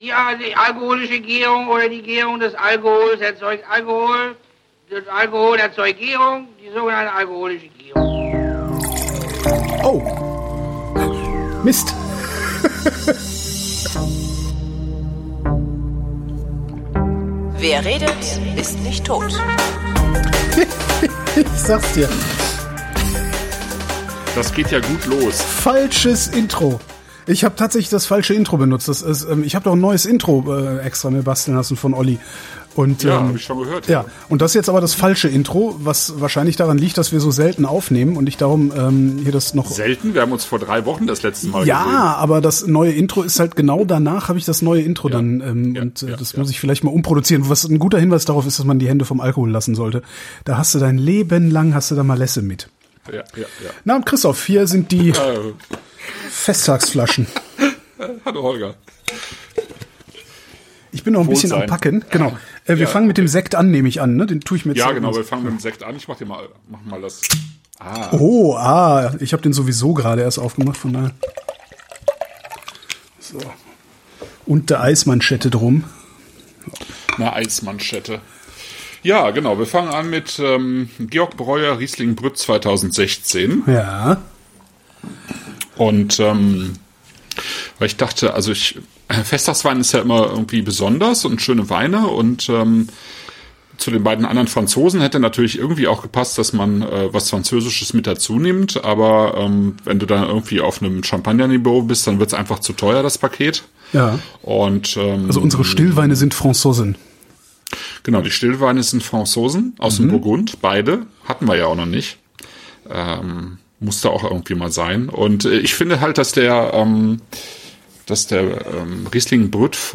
Unkonzentriert aber gut gelaunt trinken wir Breuer, Riesling Sekt Brut, Rheingau, 2016; Monthelie-Douhairet-Porcheret, »Cuvée Miss Armande«, 2019; Boisson-Vadot, Hautes Côtes de Beaune rouge, 2018 und reden über dies, das und alten Riesling.